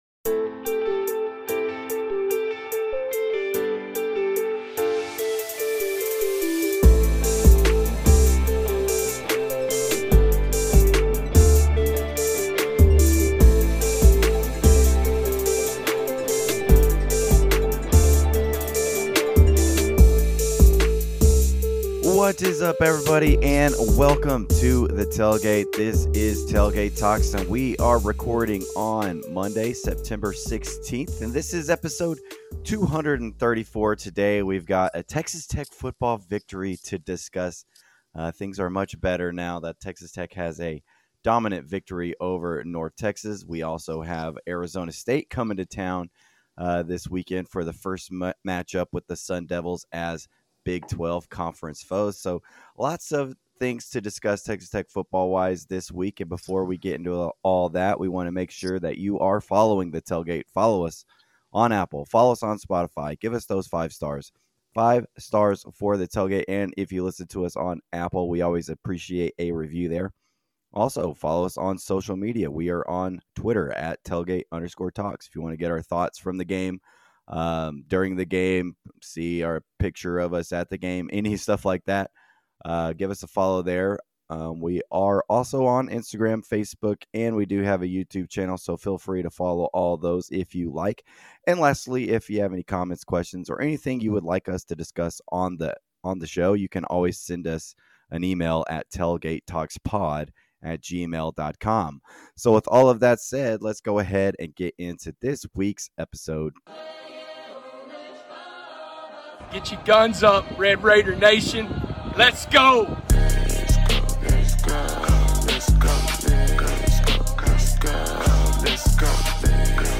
Two Texas Tech Red Raiders get together to discuss Texas Tech sports.